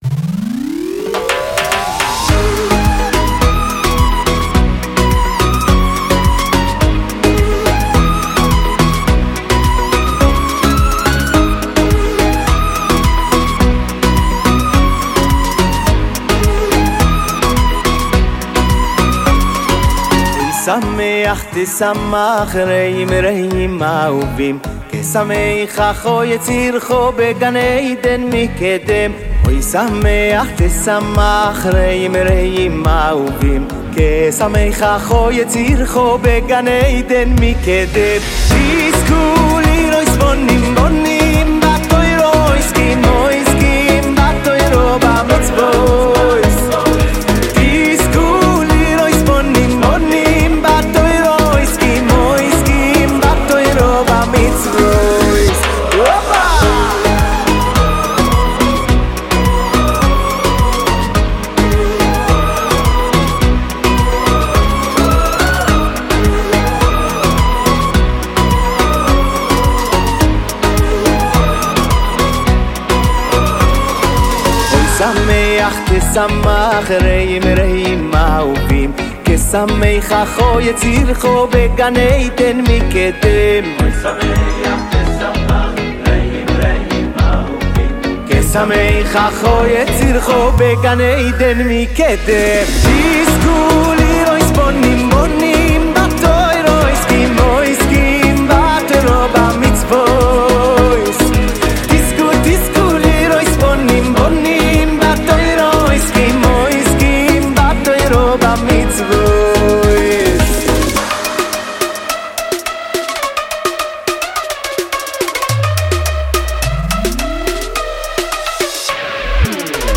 באמצעות לחן קליט ומדבק.